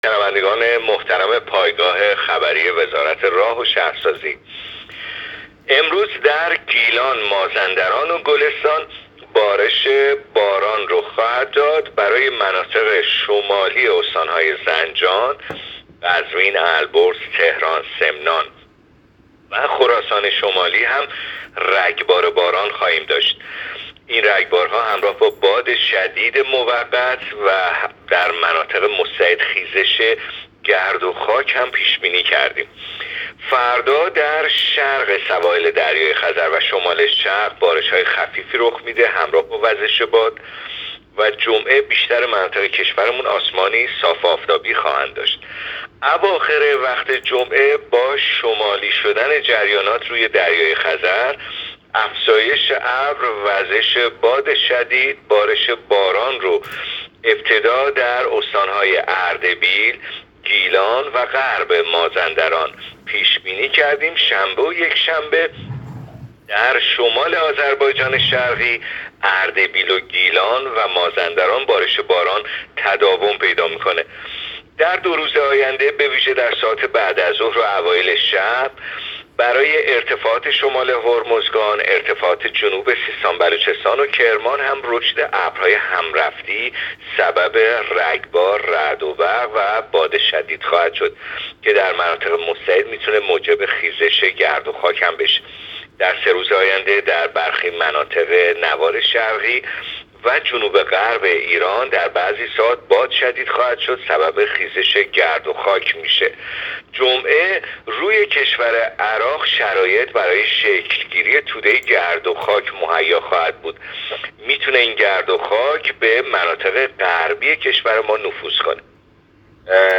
گزارش رادیو اینترنتی پایگاه‌ خبری از آخرین وضعیت آب‌وهوای دوم مهر؛